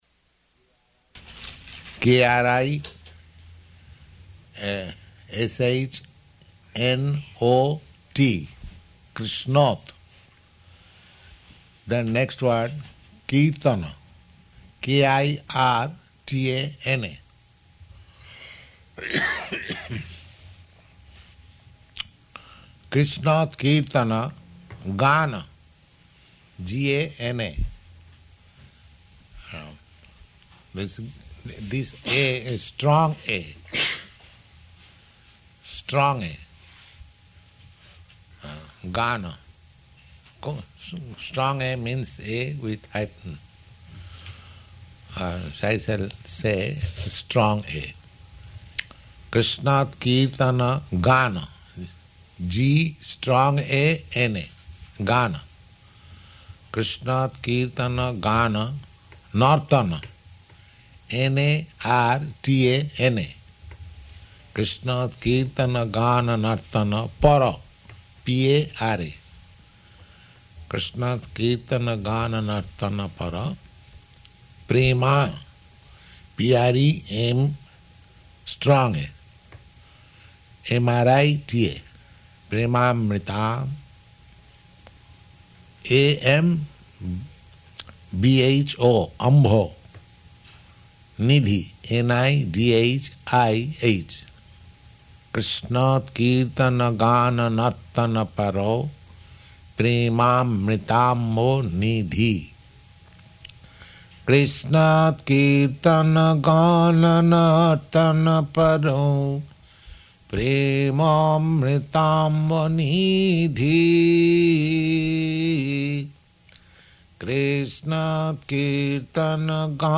Location: Los Angeles
[sings:] Kṛṣṇotkīrtana-gāna-nartana-parau premāmṛtāmbho-nidhī.